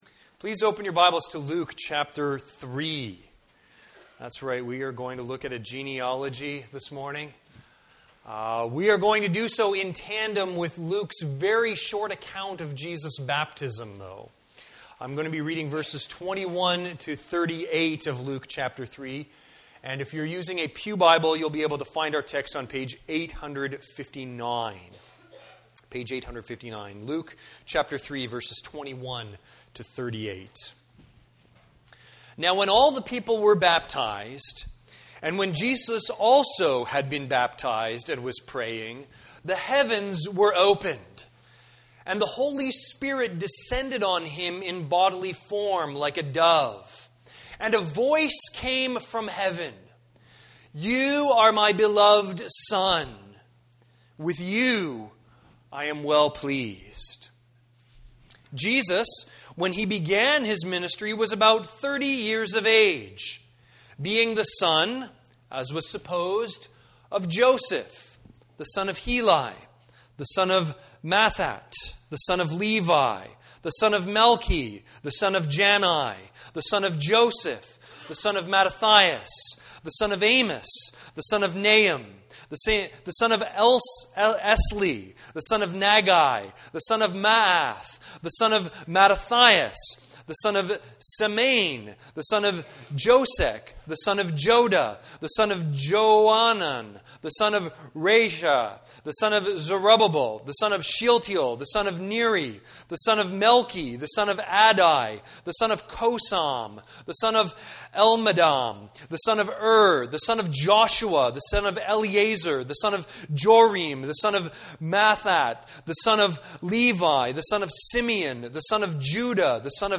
Sermons | Campbell Baptist Church
View the Sunday service.